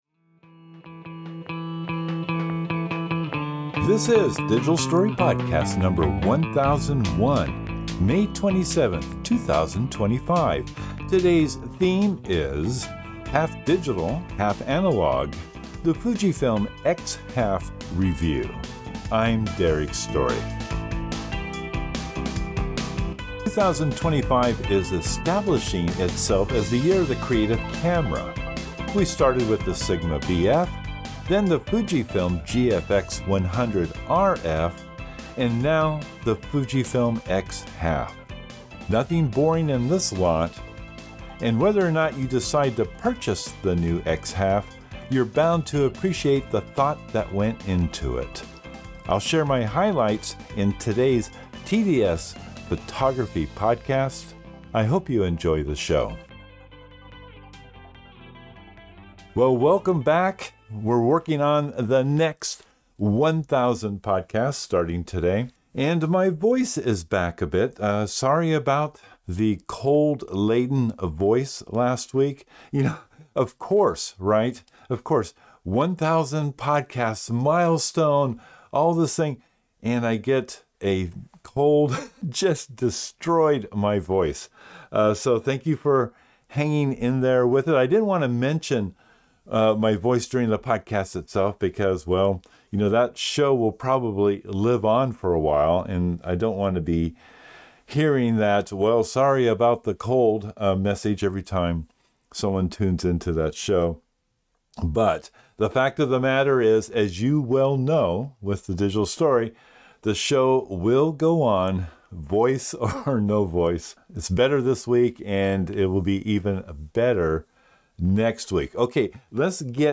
In this video podcast interview